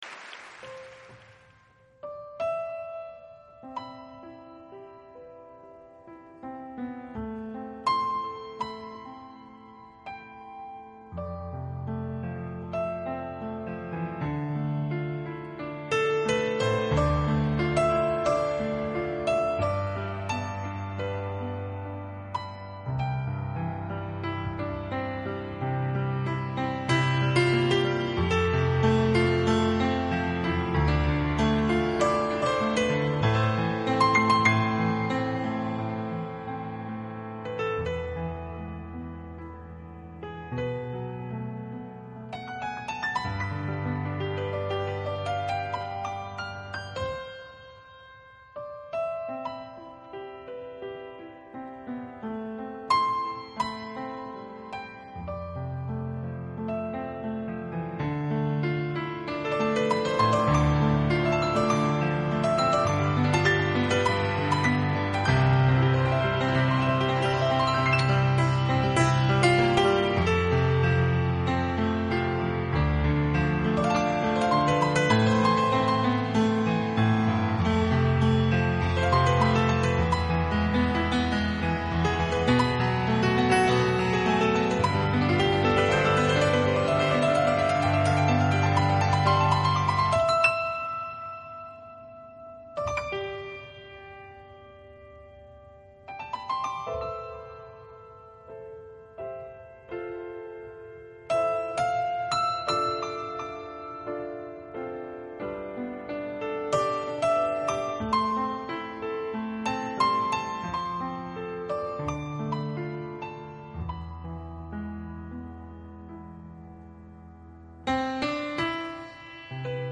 【纯美钢琴】